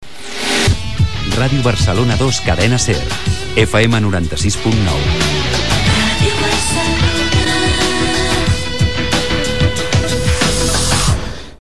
Identificació i freqüència de l'emissora